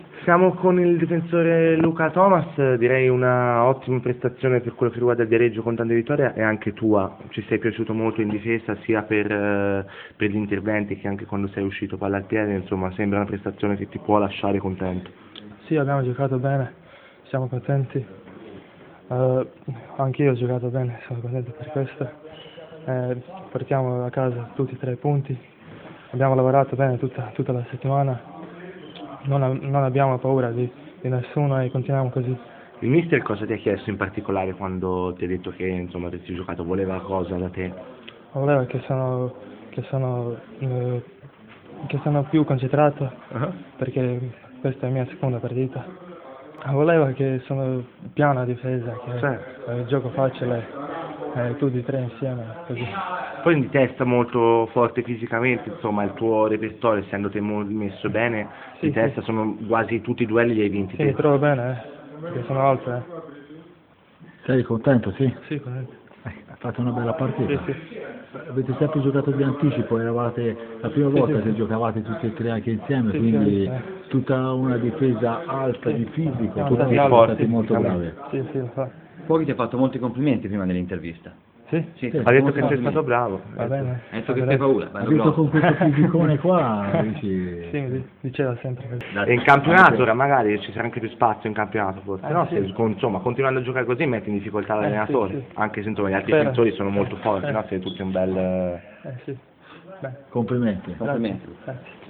Con l’italiano non se la cava ancora benissimo, complice la sua nazionalità croata.